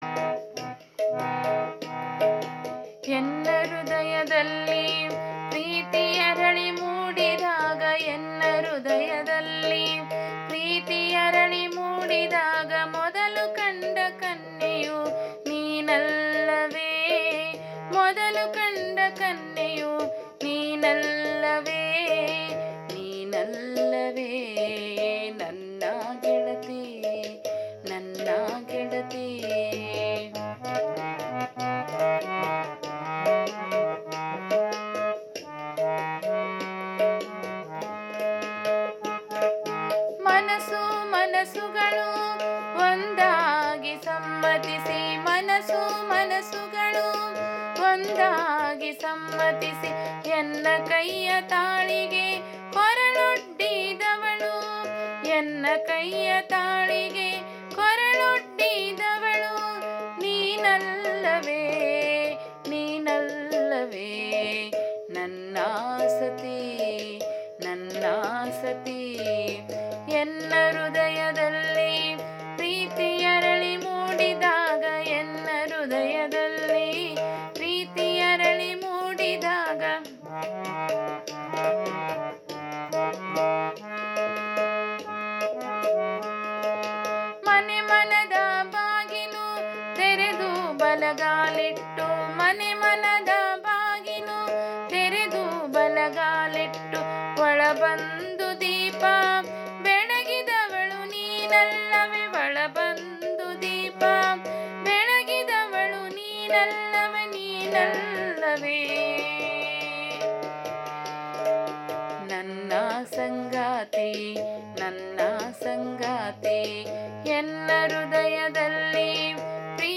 ಗಝಲ್